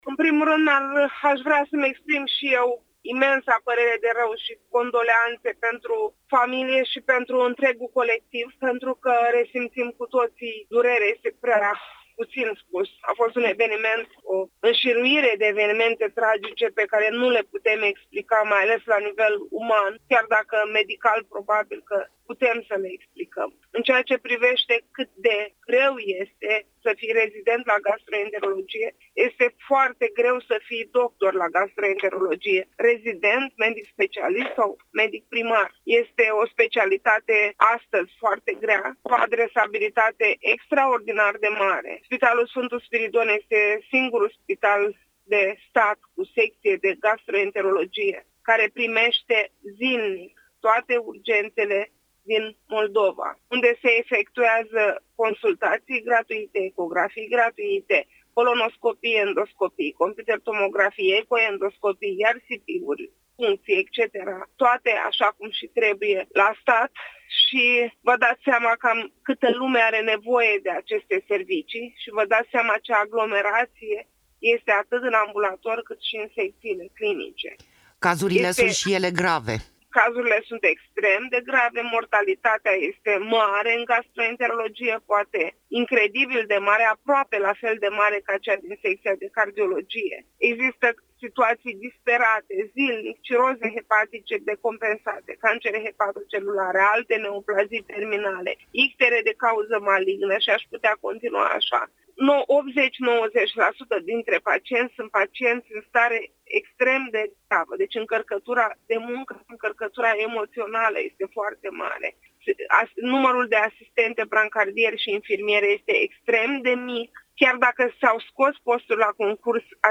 Interviu-rezidenti-gastro-iasi.mp3